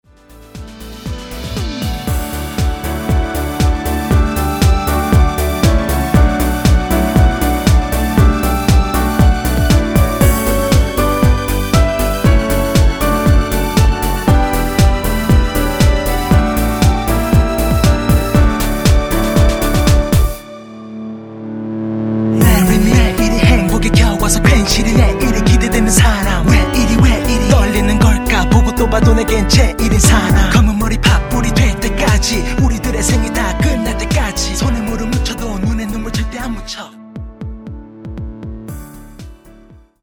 전주가 없는 곡이라 2마디 전주 만들어 놓았습니다.(원키 멜로디MR 미리듣기 참조)
원키 랩과 멜로디 포함된 MR입니다.(미리듣기 확인)
D
앞부분30초, 뒷부분30초씩 편집해서 올려 드리고 있습니다.